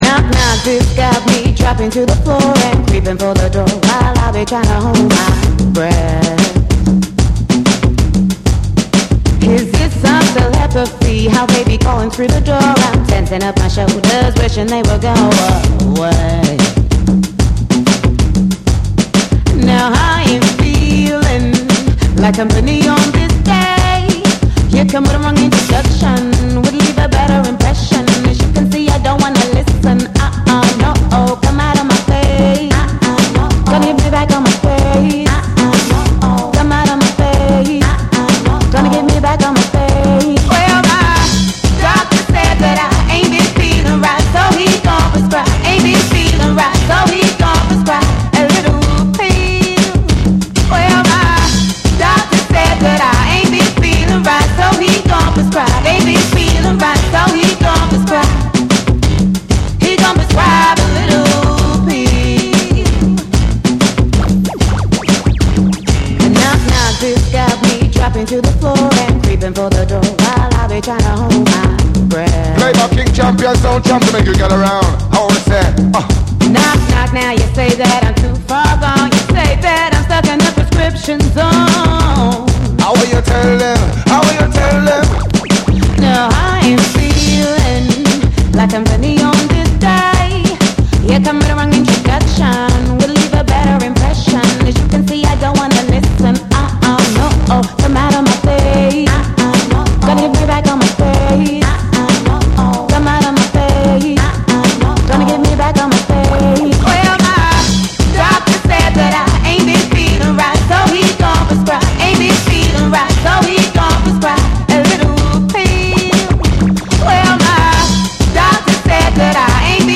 オリジナルはグルーヴィーでフューチャリスティックなダンスホール・トラック。
BREAKBEATS / REGGAE & DUB